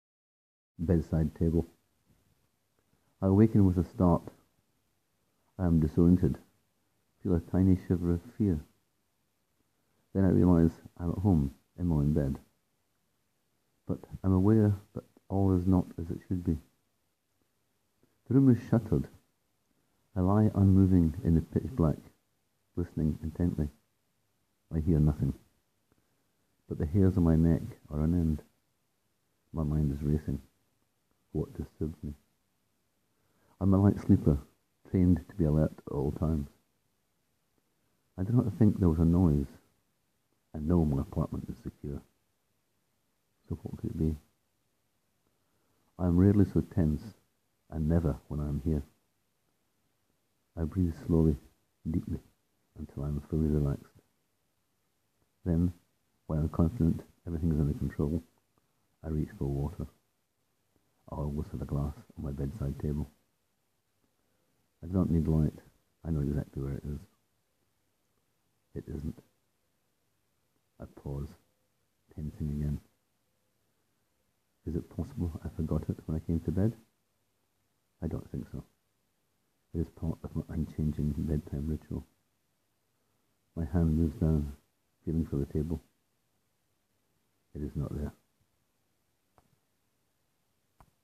And click here to hear me read this 90-second story: